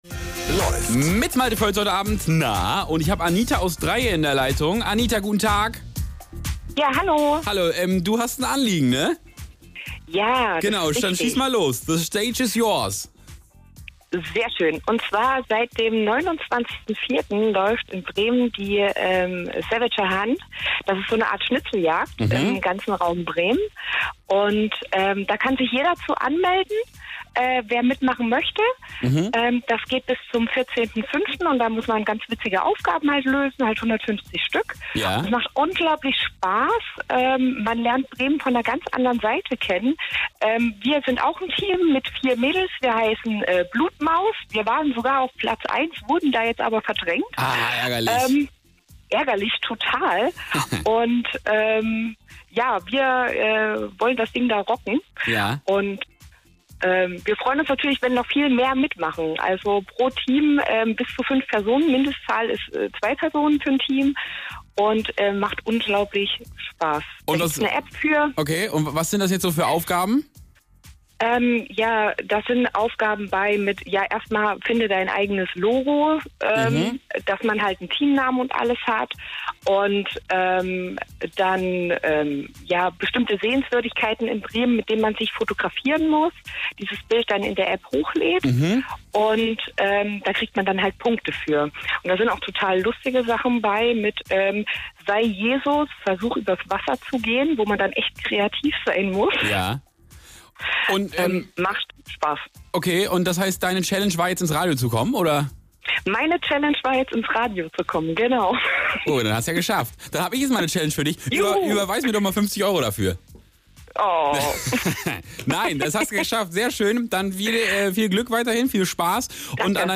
Die Bremer Hunt im Radio
Sendungsmitschnitt-Bremen-Vier.mp3